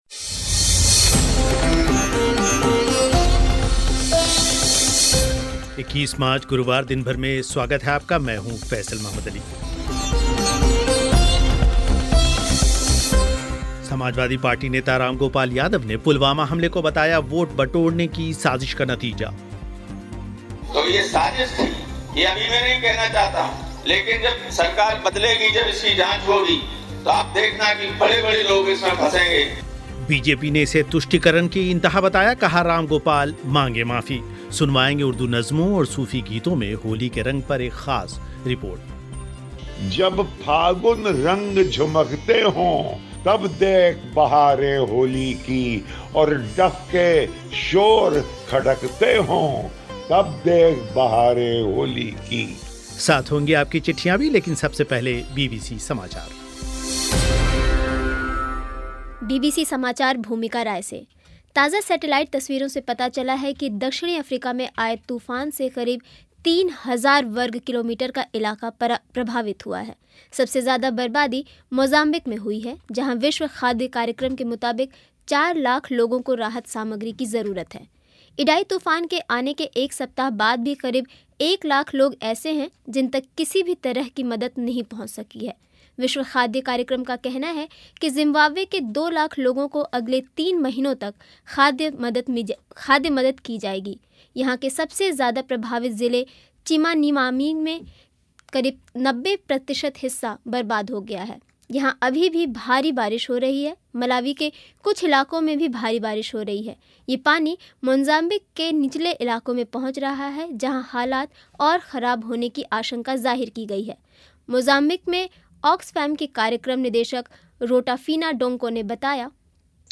साथ ही हैं आपके ख़तों के जवाब और विश्व समाचार